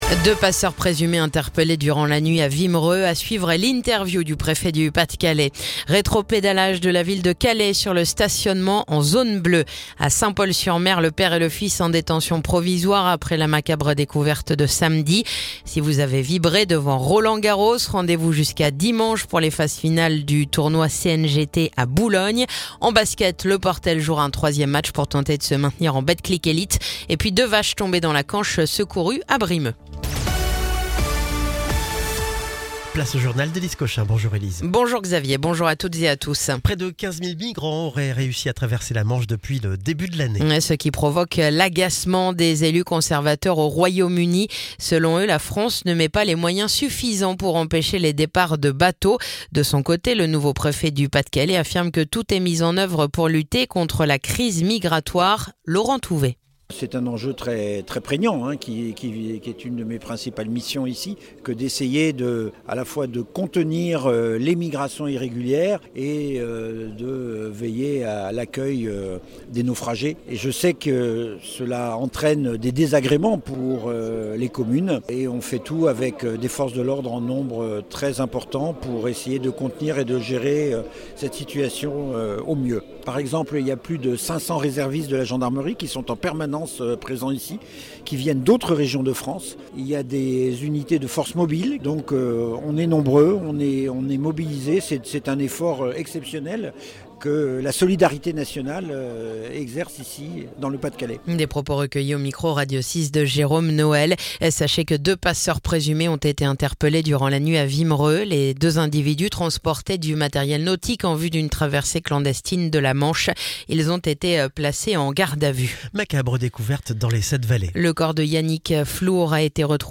Le journal du jeudi 12 juin